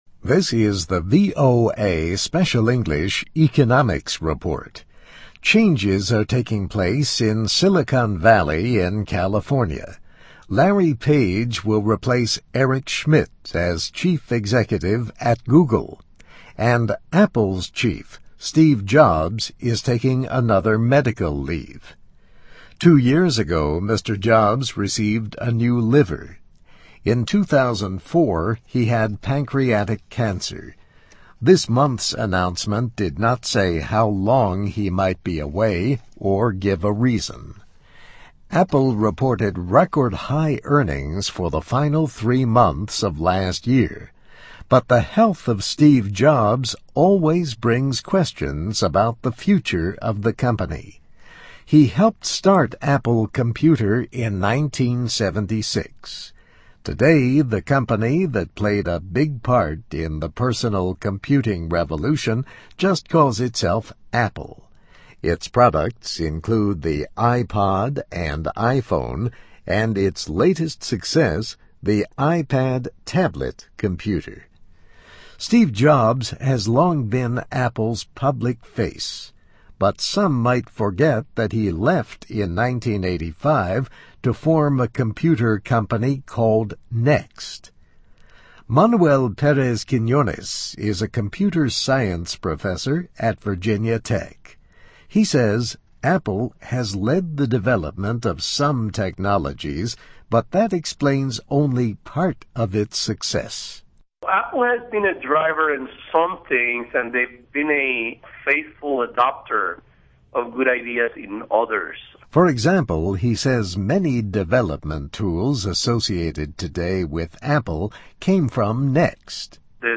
Economics Report